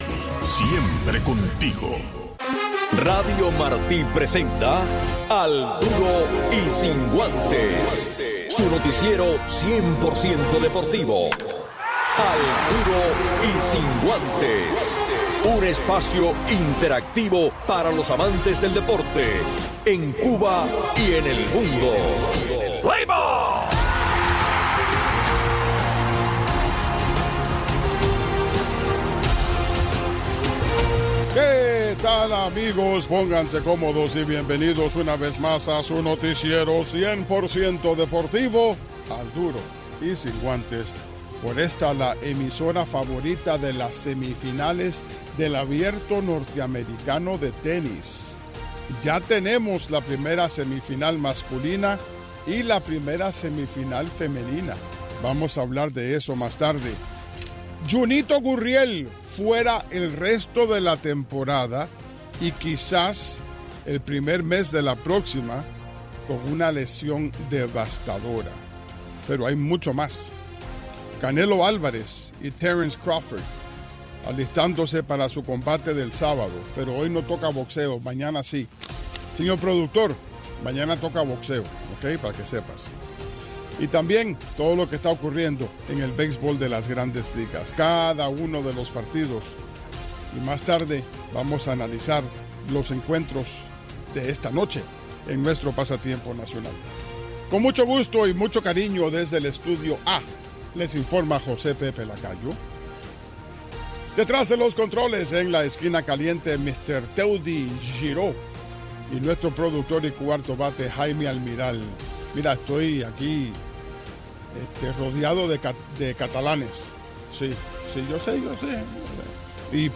Un resumen deportivo